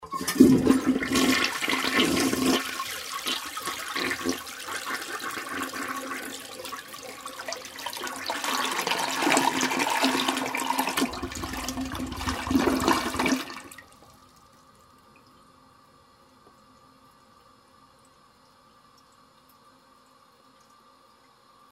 Звуки смыва унитаза